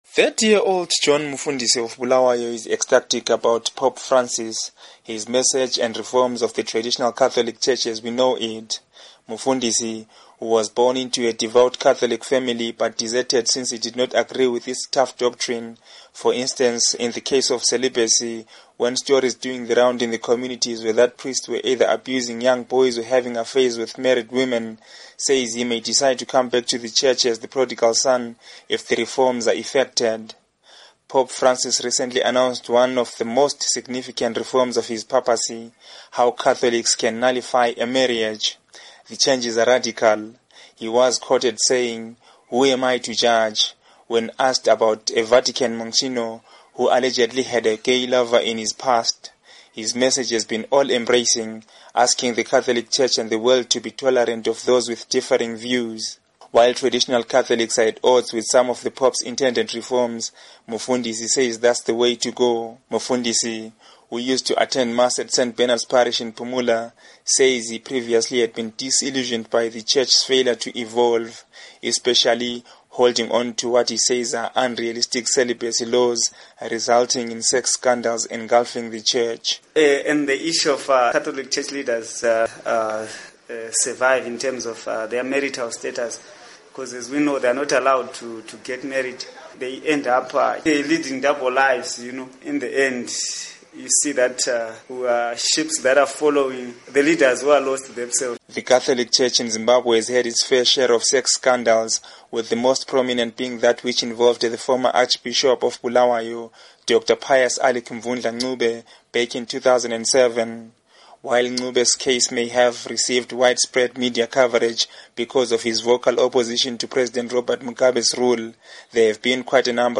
Report on Pope Visit